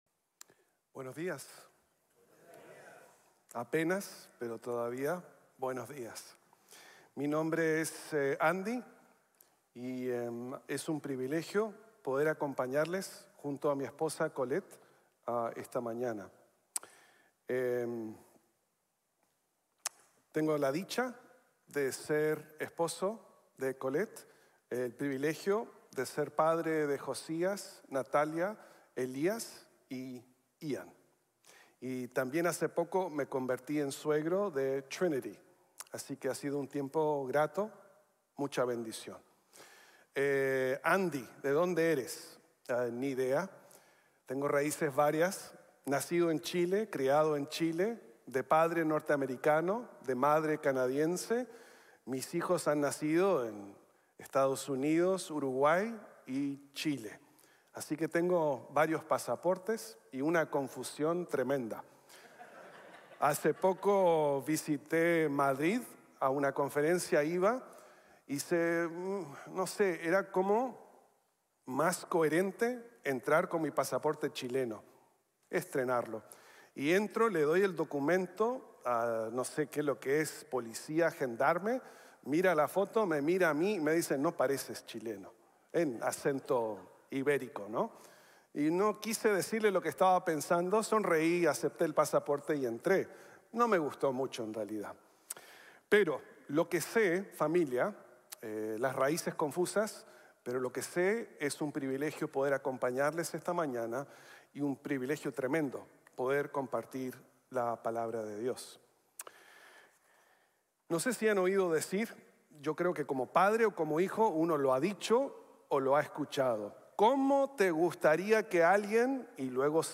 Jesus Espera Misericordia | Sermon | Grace Bible Church